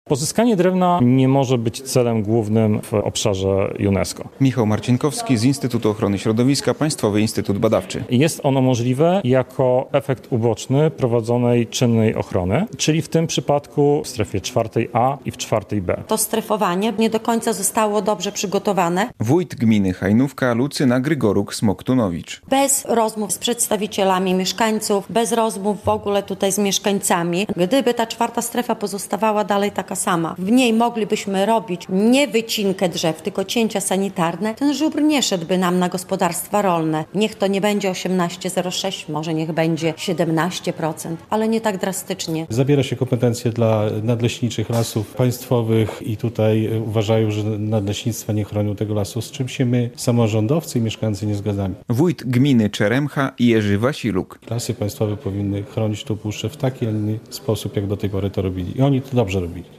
Rozpoczęły się konsultacje społeczne w sprawie planu zarządzania Puszczą Białowieską. We wtorek (8.07) w Białowieży autorzy projektu spotkali się z samorządowcami z puszczańskich gmin. Największe kontrowersje budzi podział puszczy na cztery strefy.